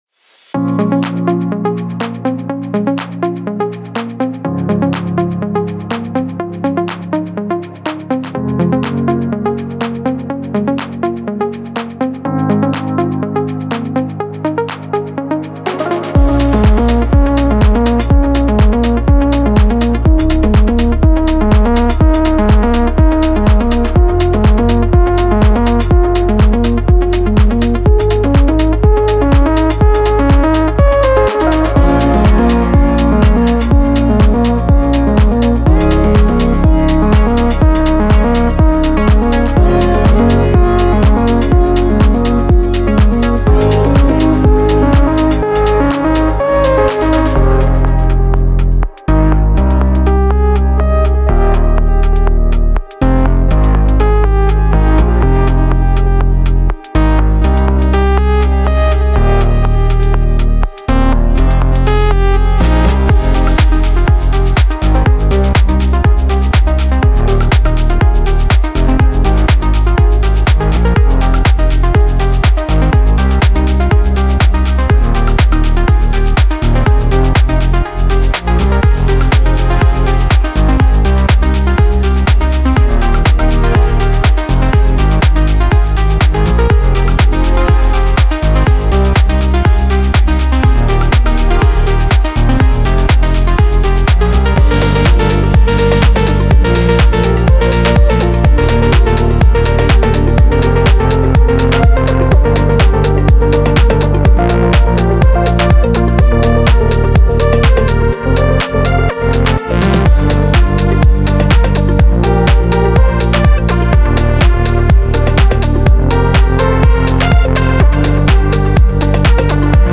7つのコンストラクションキットと多数の追加コンテンツを収録しており、美しく、感情的で、インスピレーションに満ちています。
デモサウンドはコチラ↓
Genre:House
122, 123, 124 BPM
106 Wav Loops (Basses, Synths, Drums, Fx & Full Mixes)